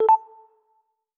Rhodes.wav